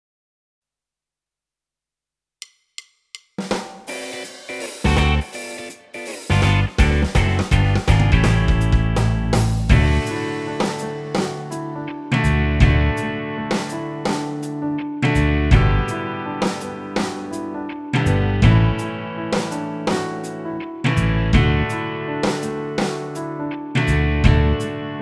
Produkt zawiera utwór w wersji instrumentalnej oraz tekst.